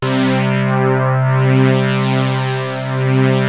JUP.8 C4   2.wav